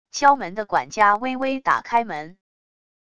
敲门的管家微微打开门wav音频